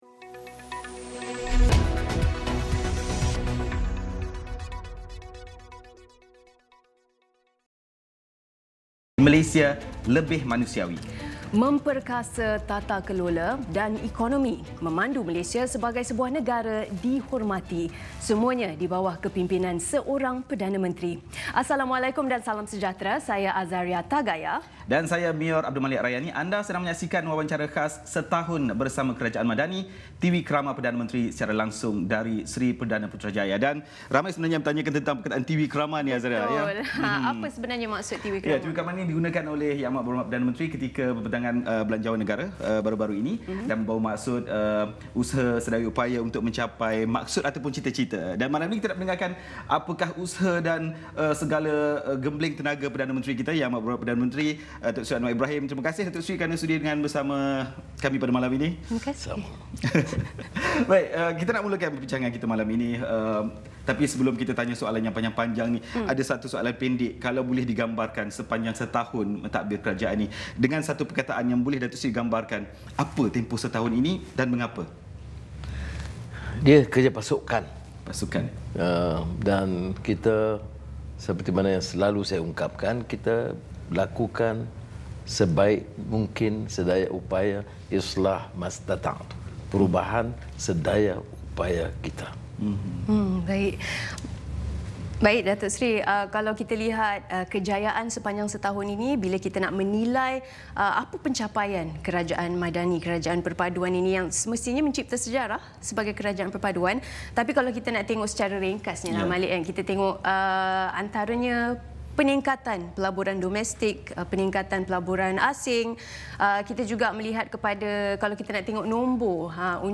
Ikuti langsung Wawancara Khas Bersama Perdana Menteri Datuk Seri Anwar Ibrahim sempena Setahun Bersama Kerajaan Madani.